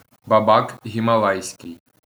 баба́к гімала́йський (1.1)
wymowa: